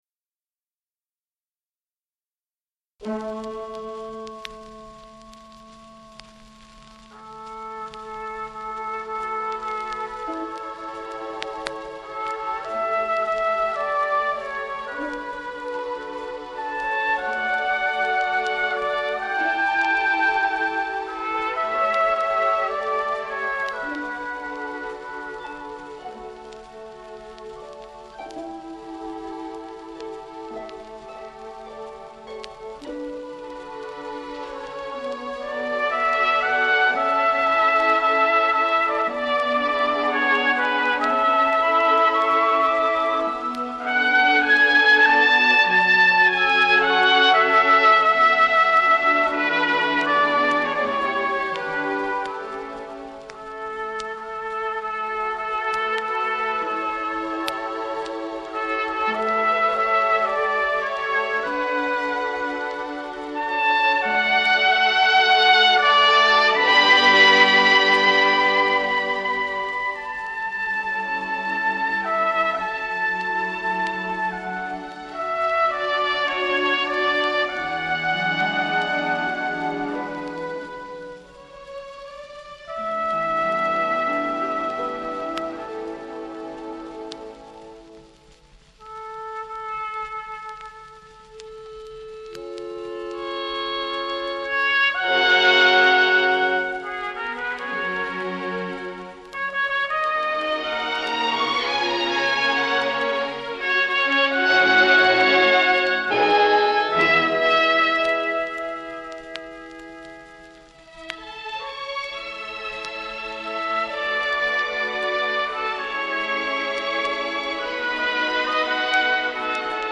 2. Ideal dynamic range plus clarity and brilliance.